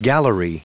Prononciation du mot gallery en anglais (fichier audio)
Prononciation du mot : gallery